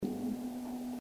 Témoignages sur la construction navale à Fécamp